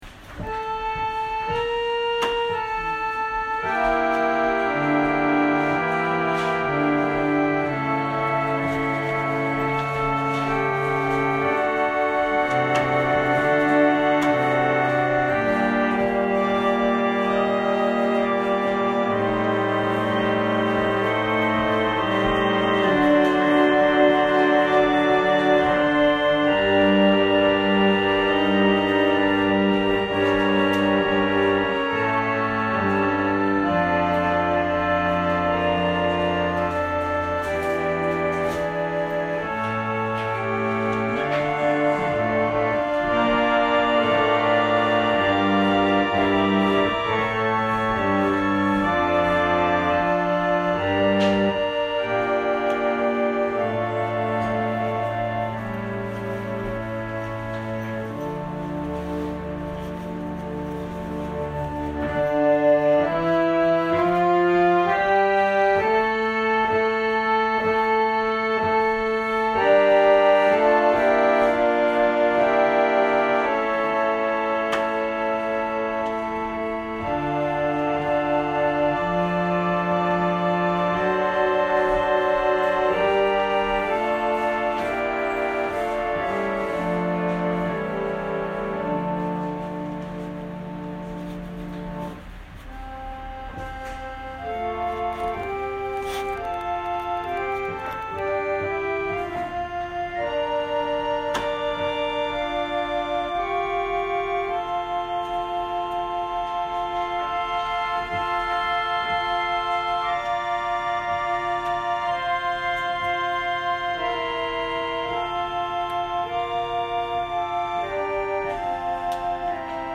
説教アーカイブ。
私たちは毎週日曜日10時20分から12時まで神様に祈りと感謝をささげる礼拝を開いています。